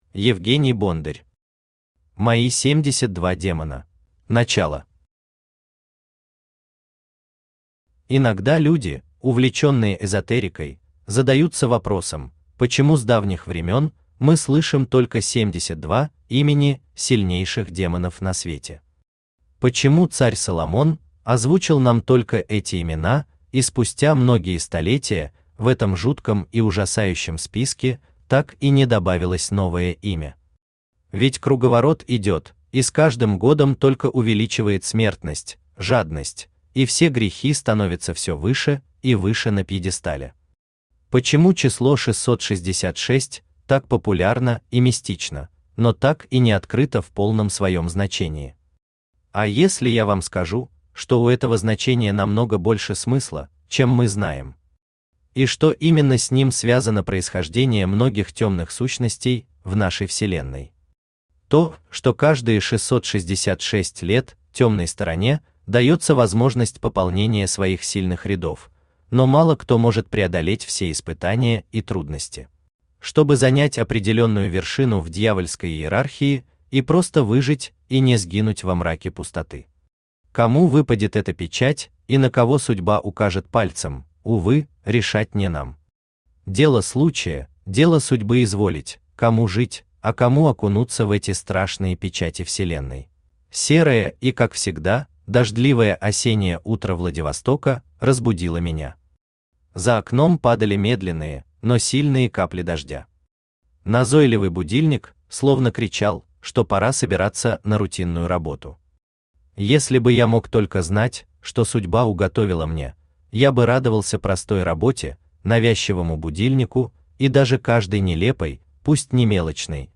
Аудиокнига Мои семьдесят два демона | Библиотека аудиокниг
Aудиокнига Мои семьдесят два демона Автор Евгений Валерьевич Бондарь Читает аудиокнигу Авточтец ЛитРес.